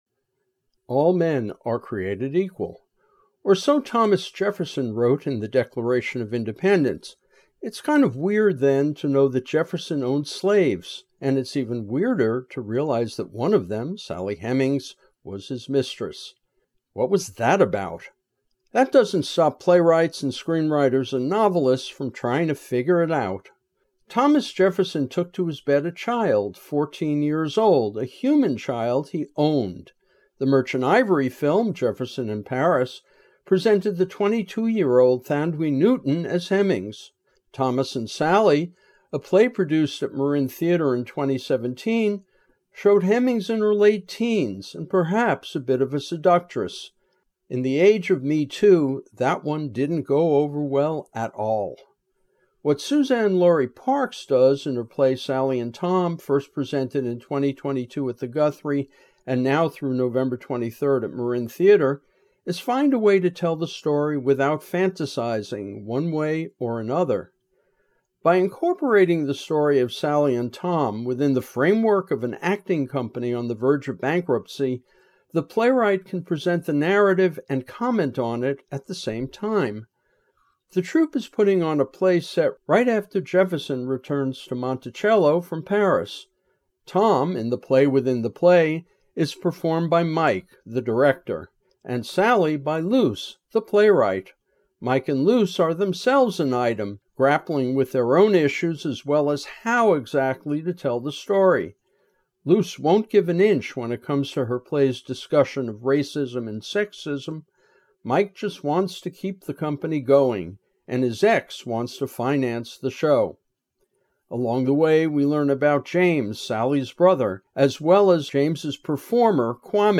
Review: “Sally and Tom” at Marin Theatre 2025-11-10